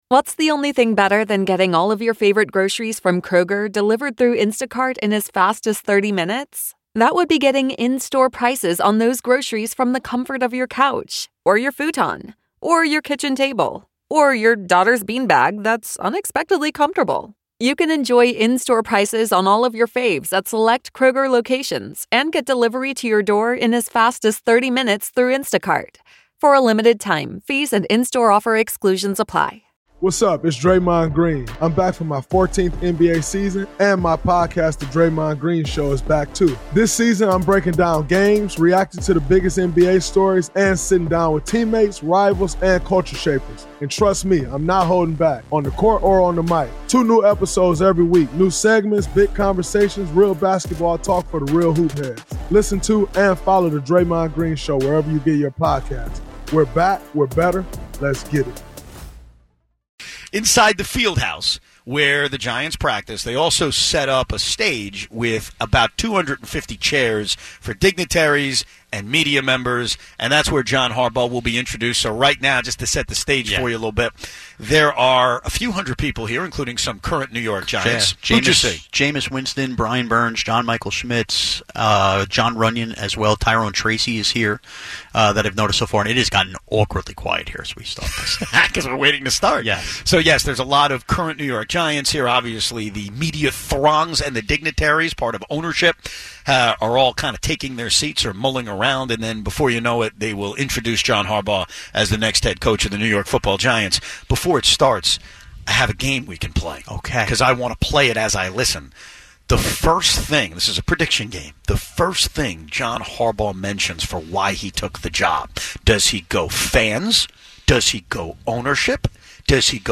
Live from the Giants fieldhouse, the scene is set with hundreds in attendance, current players in the building, and the room going silent as the organization prepares to introduce its next head coach.